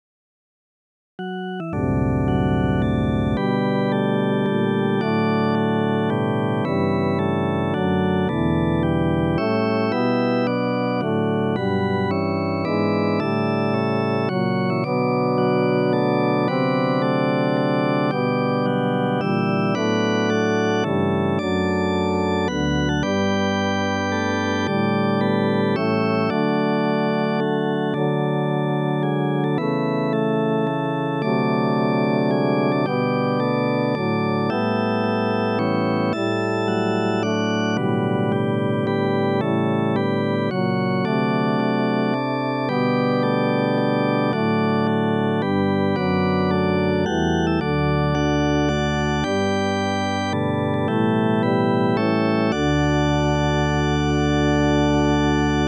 Now Let Us Rejoice, piano and organ freeforms
The first is intended for organ to be used on the final verse of the congregational hymn, "Now Let Us Rejoice." The second is for piano, but can be adapted for organ, also.
Voicing/Instrumentation: Organ/Organ Accompaniment , Piano Solo We also have other 18 arrangements of " Now Let Us Rejoice ".